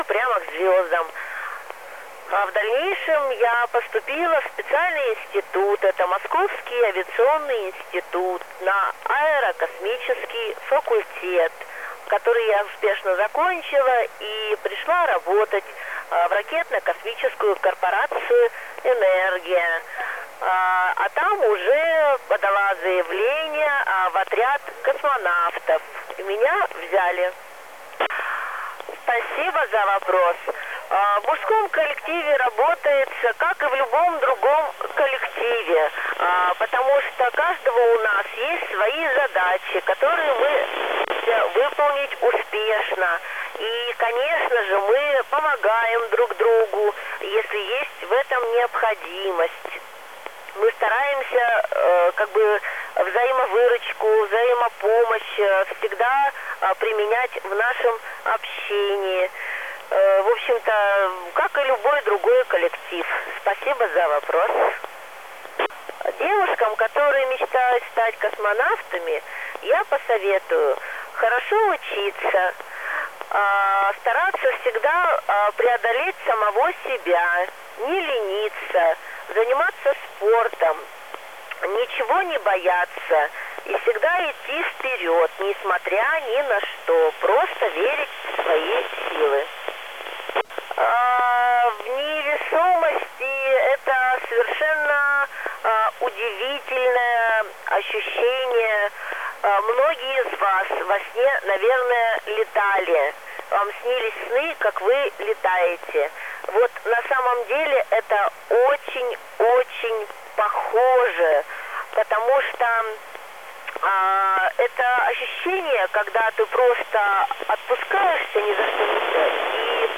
Начало » Записи » Записи радиопереговоров - МКС, спутники, наземные станции